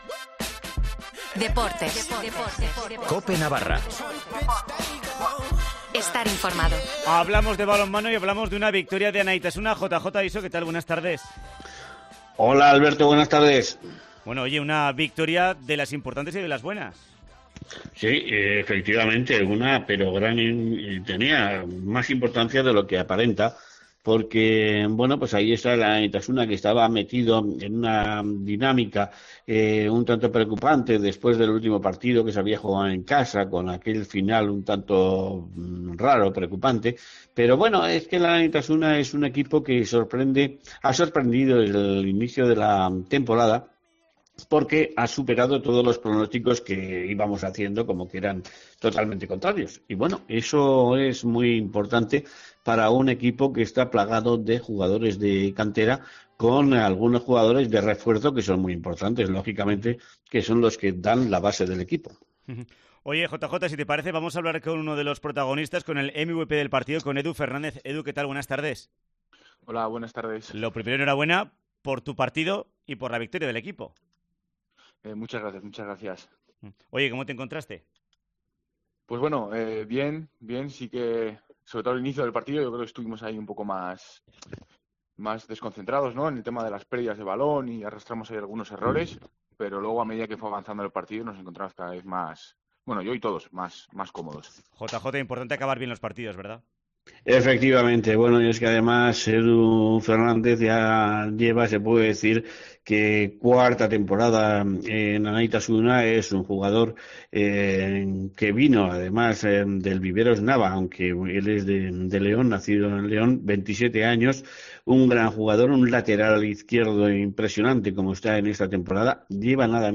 habla en COPE Navarra